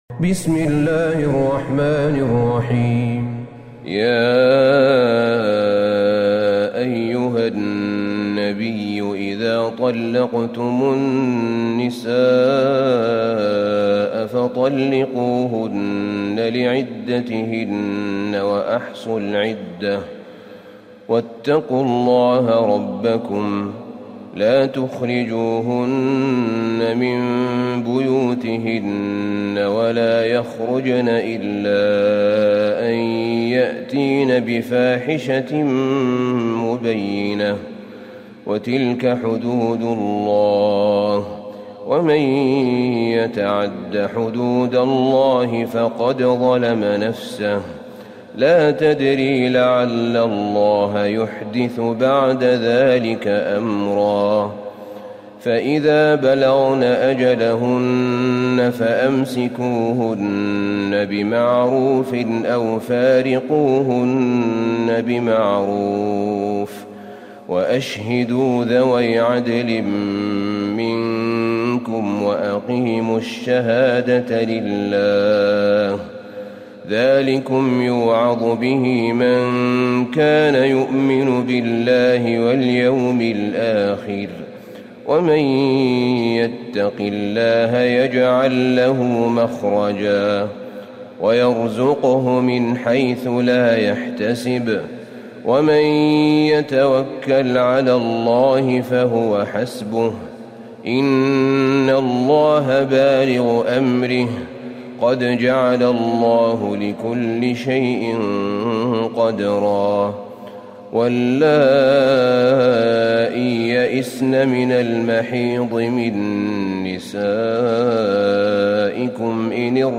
سورة الطلاق Surat At-Talaq > مصحف الشيخ أحمد بن طالب بن حميد من الحرم النبوي > المصحف - تلاوات الحرمين